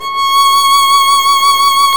Index of /90_sSampleCDs/Roland L-CD702/VOL-1/STR_Violin 1 vb/STR_Vln1 _ marc
STR VLN MT0Q.wav